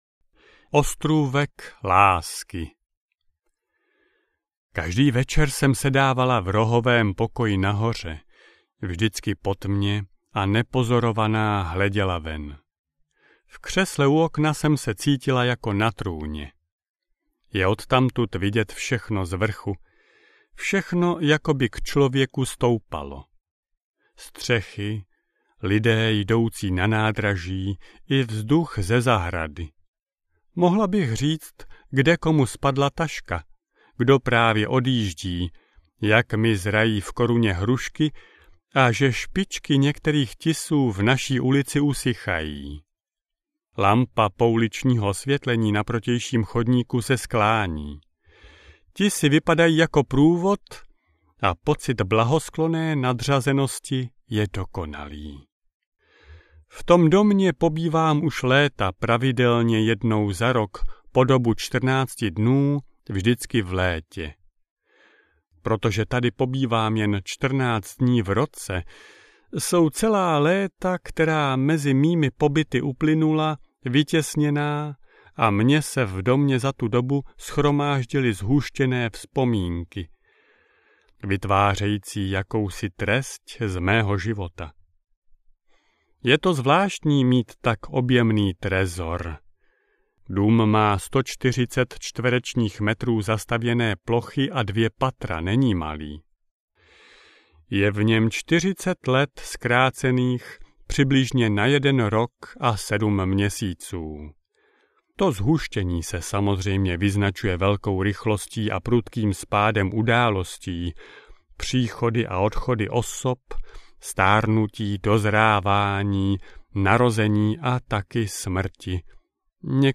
Jen já a ty audiokniha
Ukázka z knihy